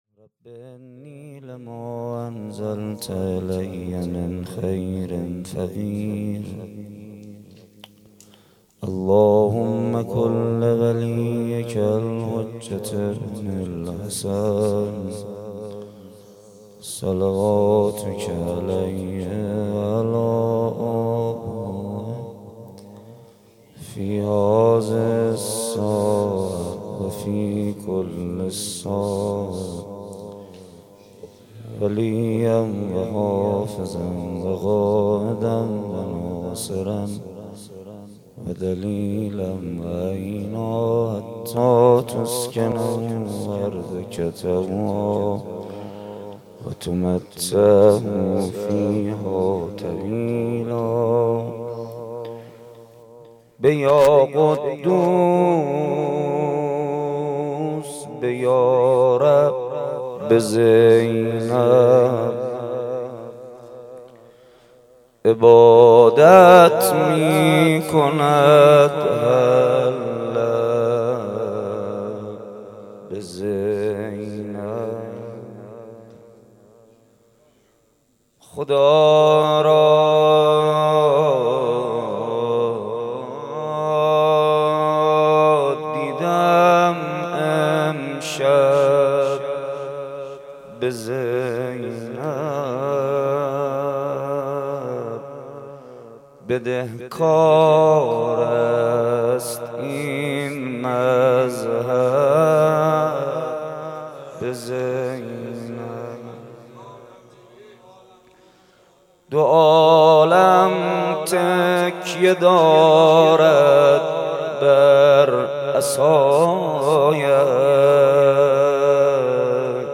شب چهارم محرم95/هیئت عبدالله بن حسن(ع)